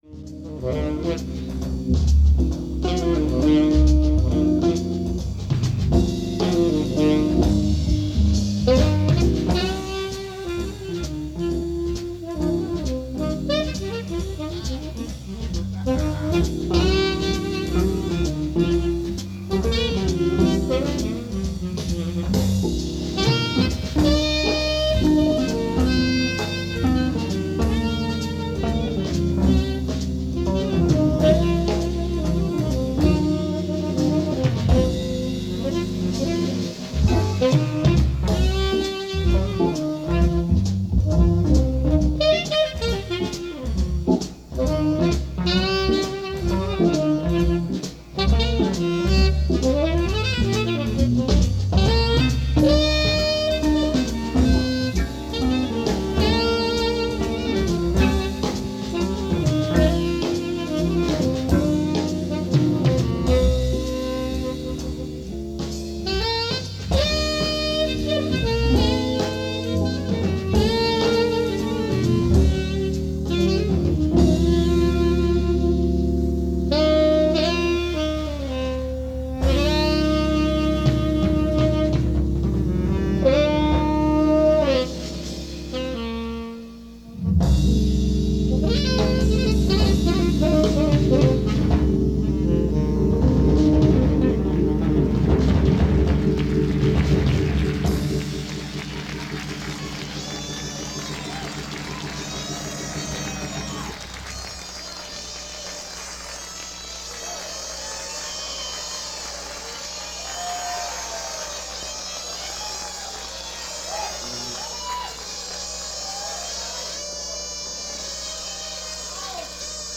- Recorded New Years Eve 1985.
Drums
guitar
master of Mellow.